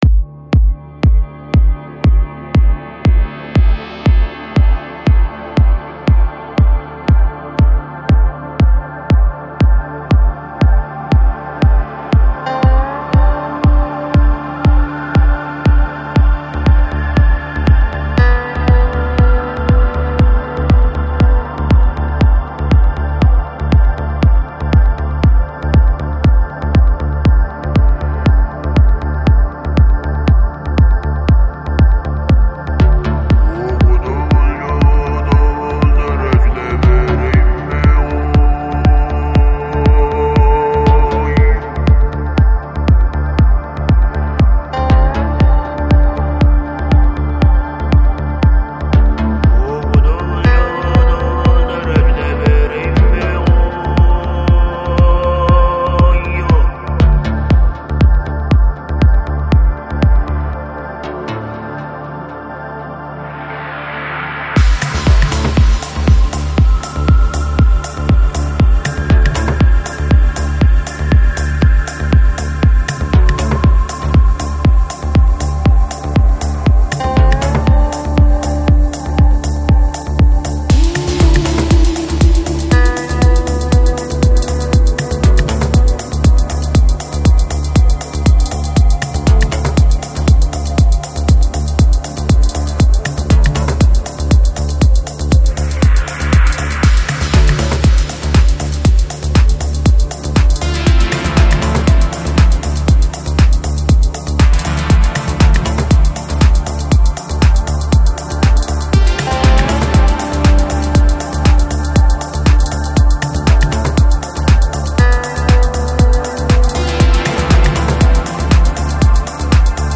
Жанр: World, Ethnic, Oriental Folk